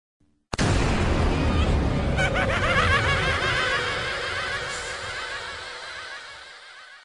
Cod Zombies Evil Laugh Sound Button - Free Download & Play